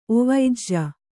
♪ ovaijja